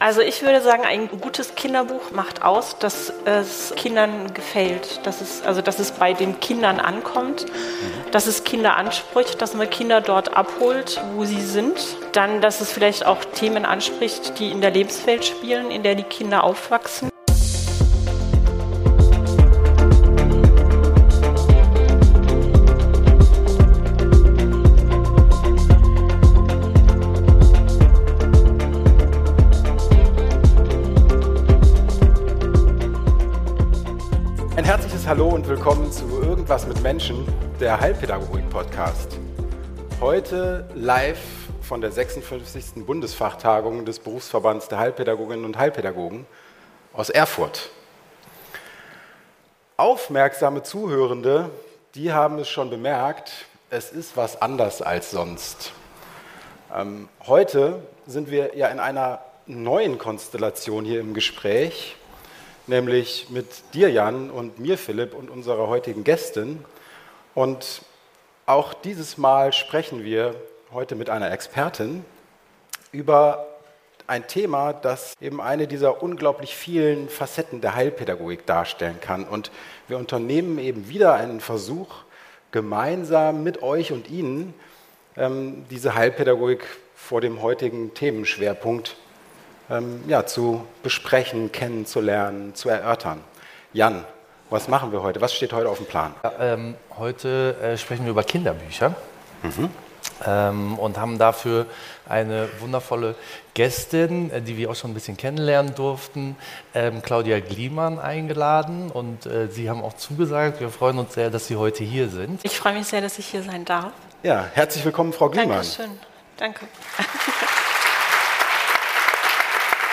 Das spannende Gespräch über die vielen Facetten der Kinderbuchproduktion, von der Konzeption bis hin zur Gestaltung, wurde live auf der 56. Bundesfachtagung des Berufs- und Fachverbands Heilpädagogik aufgenommen.